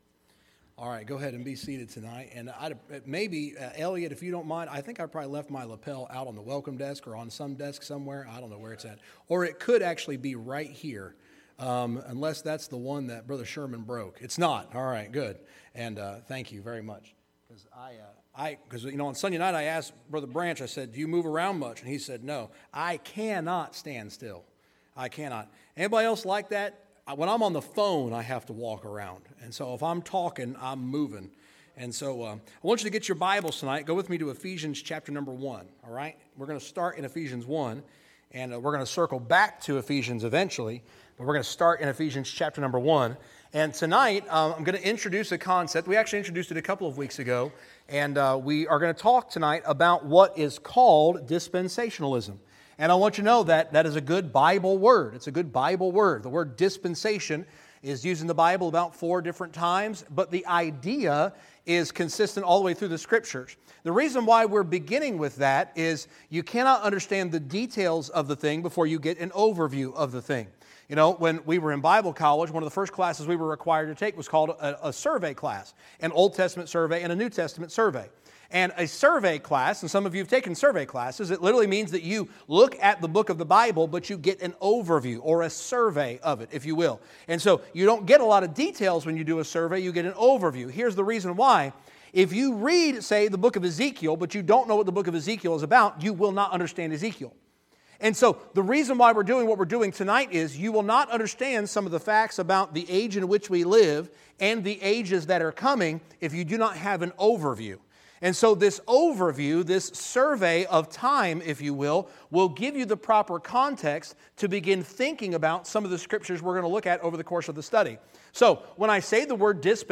Sermons | Victory Hill Baptist Church